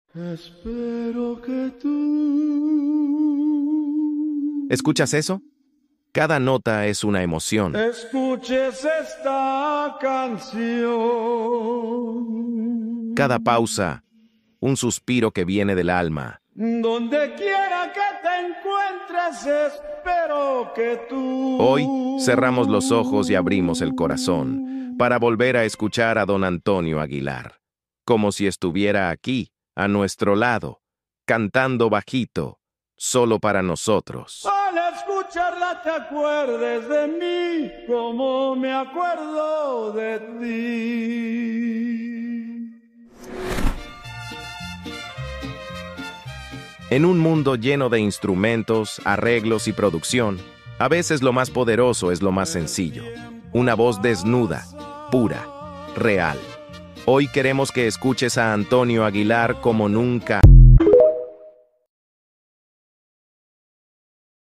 ranchera